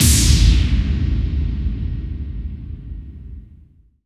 SI2 BOOF.wav